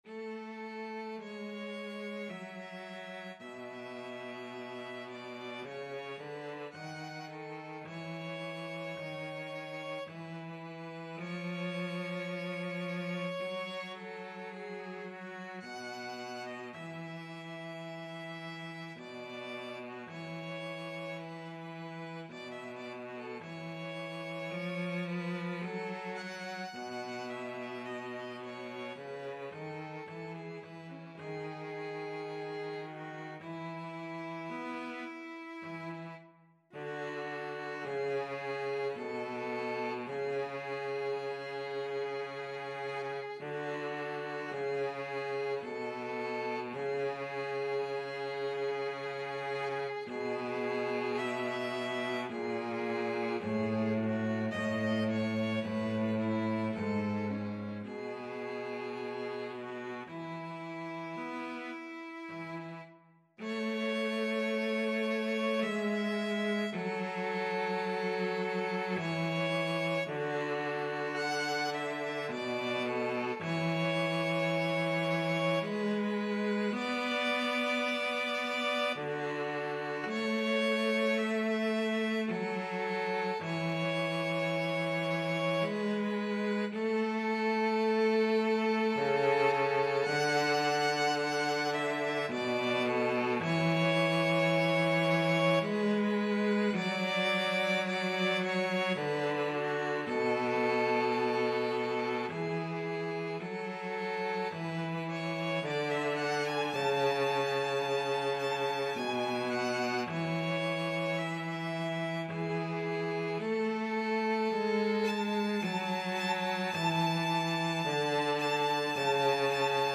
3/4 (View more 3/4 Music)
Andante sostenuto ( = 54)
Classical (View more Classical Violin-Cello Duet Music)